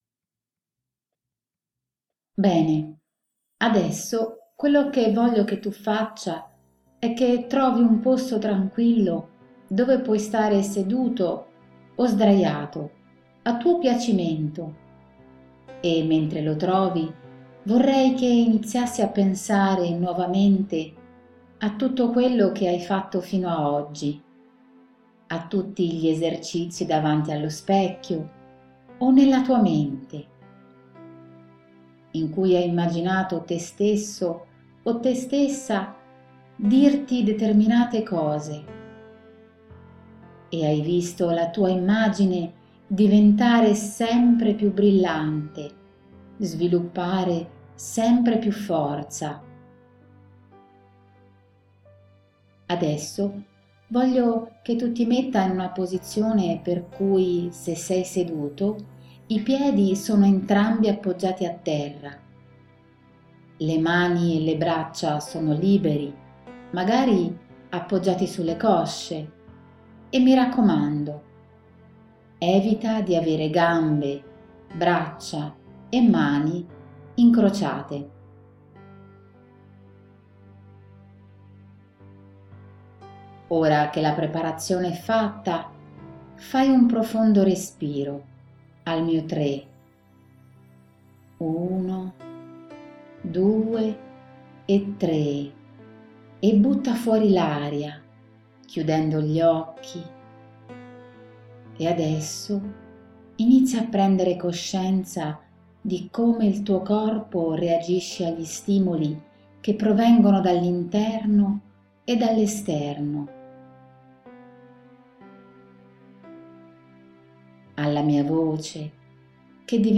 audio_induzione.mp3